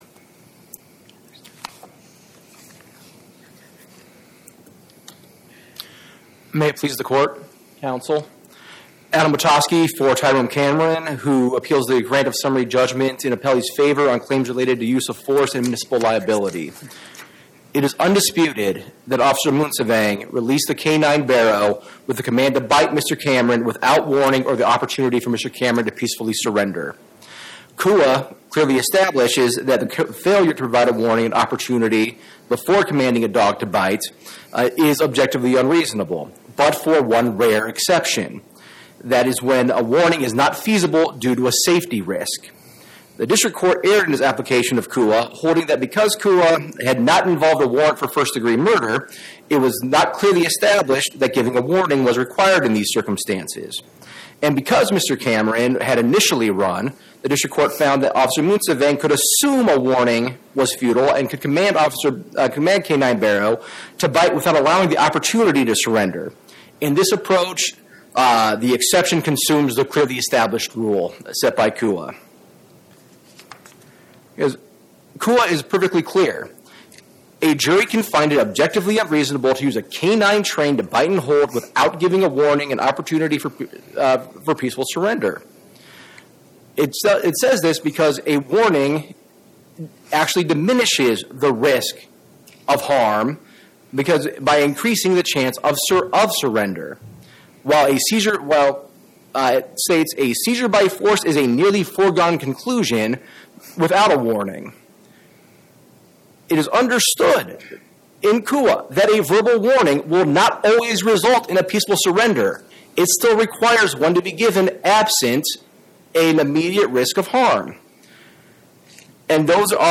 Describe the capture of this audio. Oral argument argued before the Eighth Circuit U.S. Court of Appeals on or about 12/16/2025